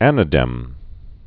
Pronunciation: